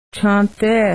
You will need to download Windows Media Player to hear the spoken words, and sentences.
Cante Heart Caun-Te
cante.wma